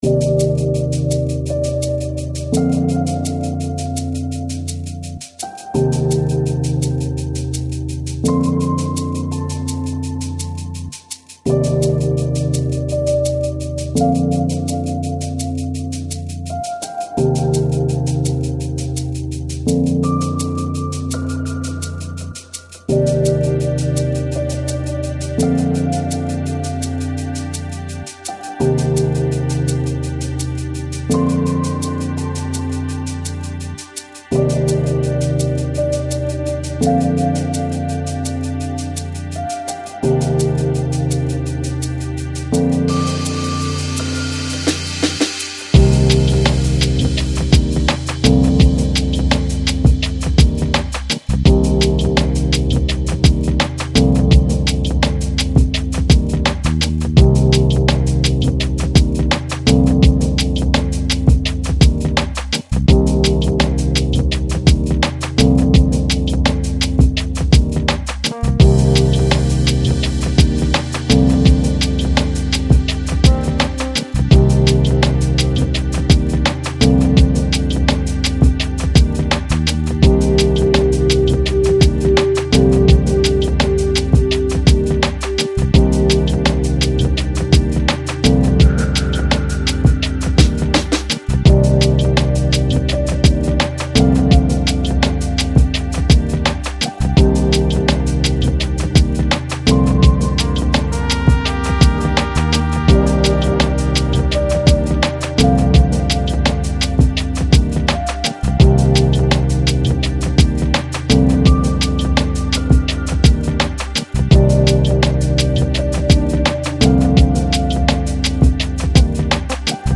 Жанр: Chill Out, Lounge, Downtempo